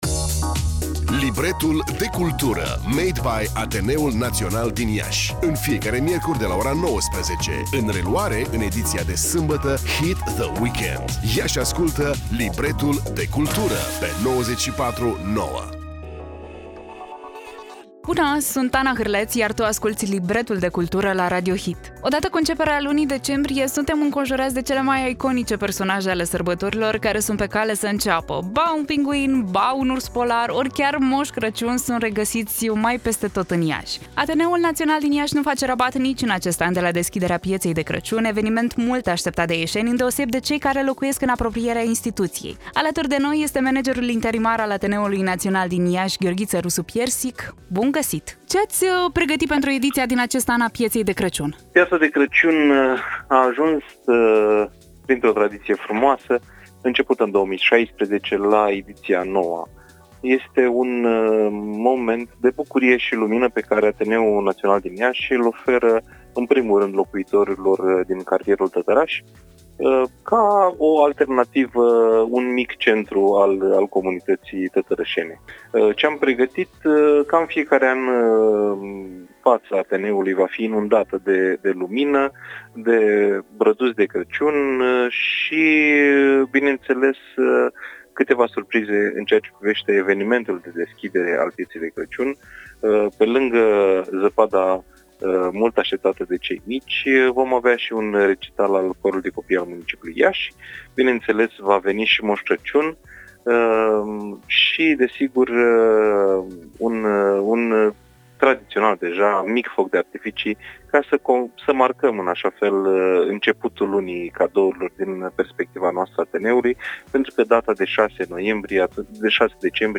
Ce ați pregătit pentru ediția din acest an a Pieței de Crăciun?